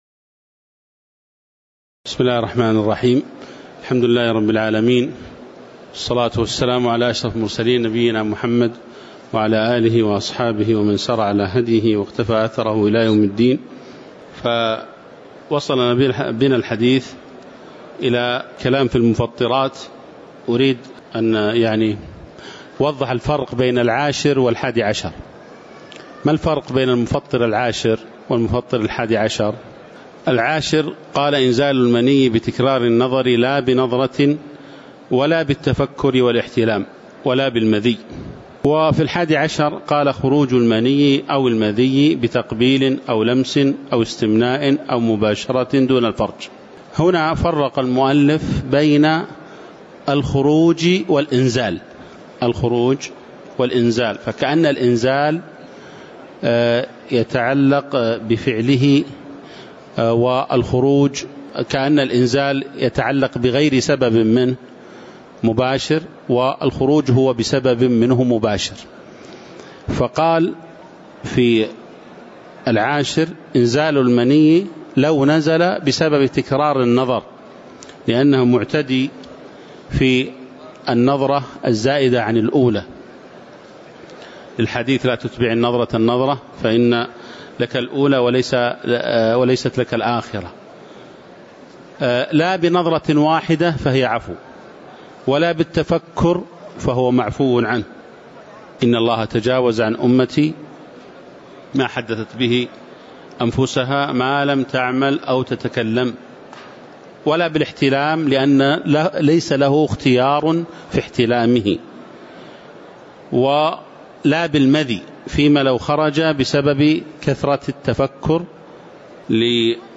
تاريخ النشر ٦ رمضان ١٤٤٠ هـ المكان: المسجد النبوي الشيخ